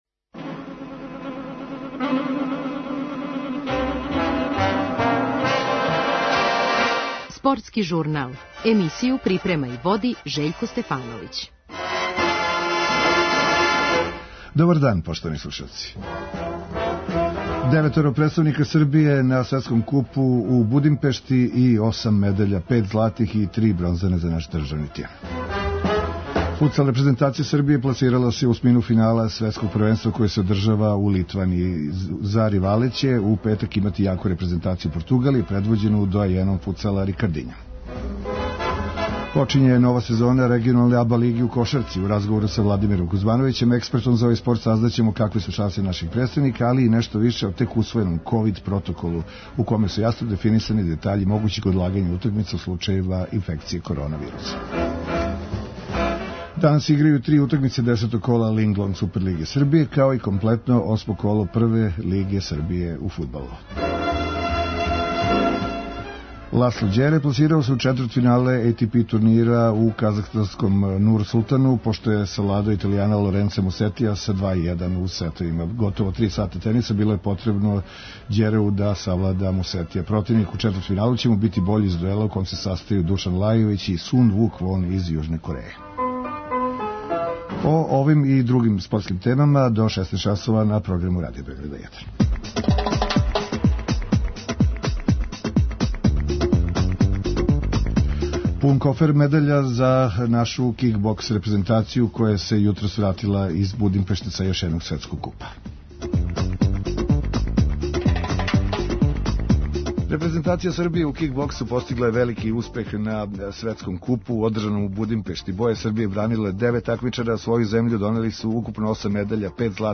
У разговору за наш програм